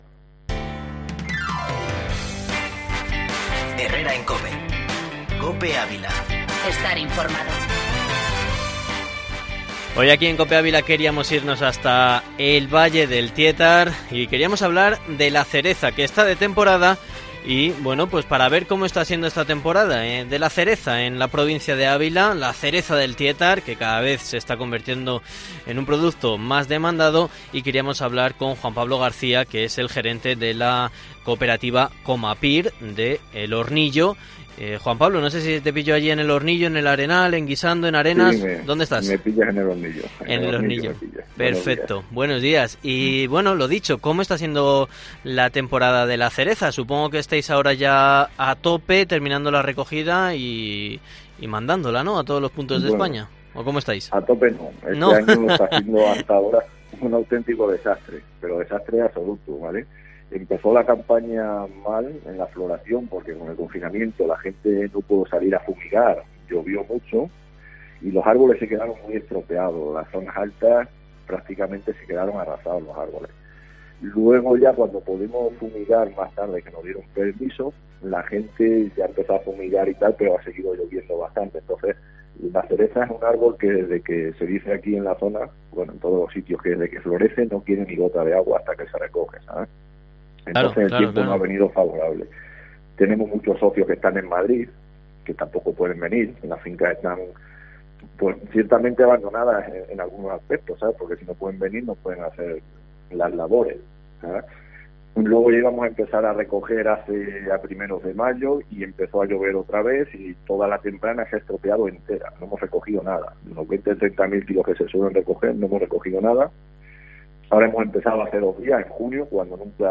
Entrevista en COPE